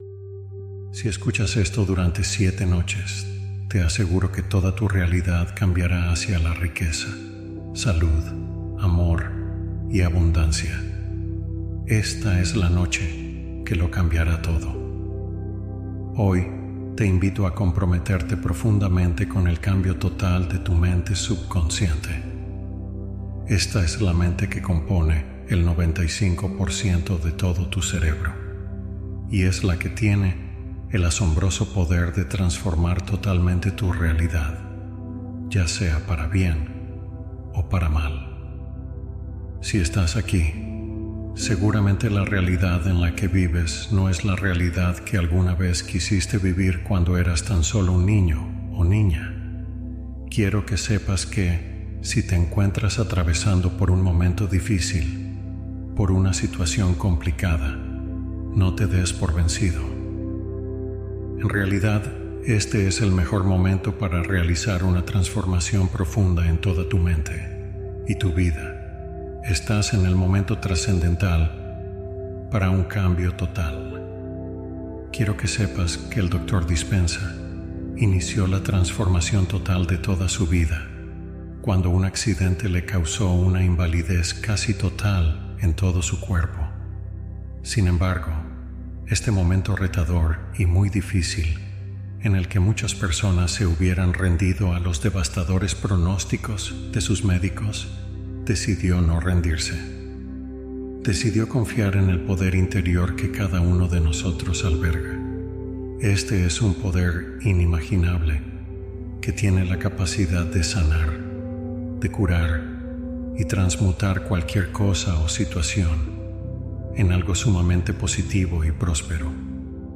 Viaje Cuántico a Otras Dimensiones en Una Noche | Meditación Guiada